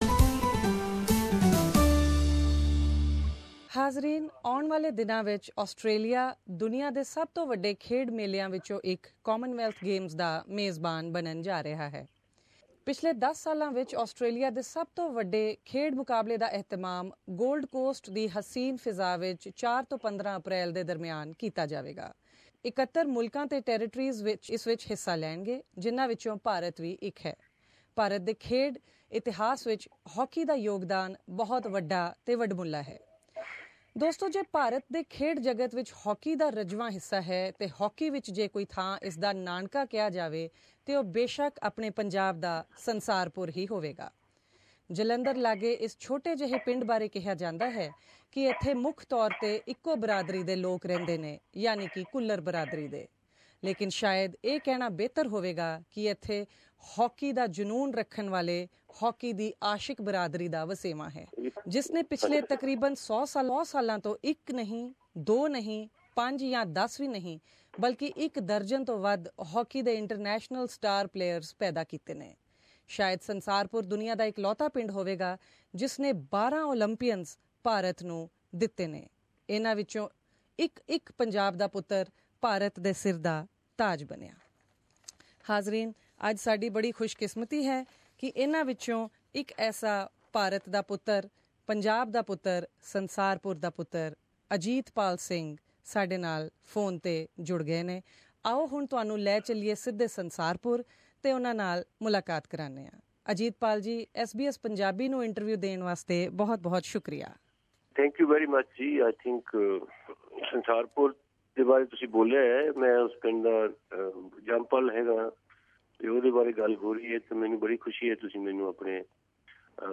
SBS Punjabi caught up with Ajit Pal Singh, one of these Olympians over the phone from Sansarpur and learnt more about the culture of hockey that runs deep in the place.